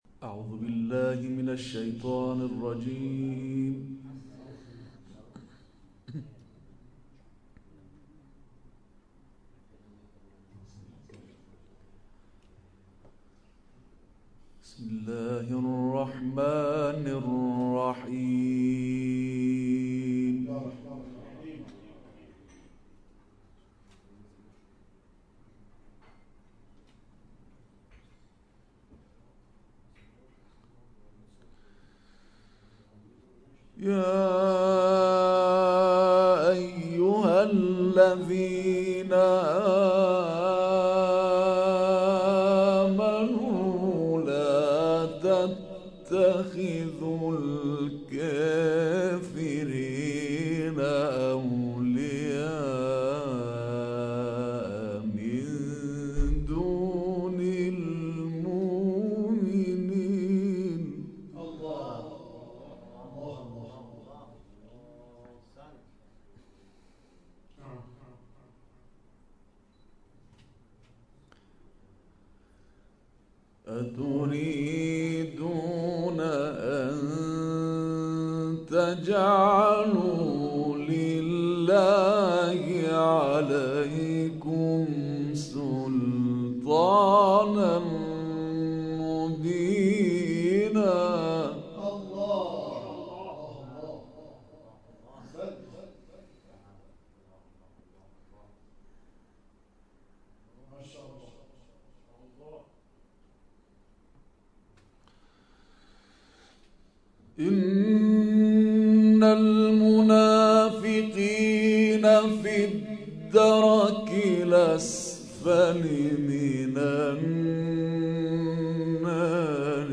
جدیدترین تلاوت
تلاوت مجلسی با استفاده از قرائات سبعة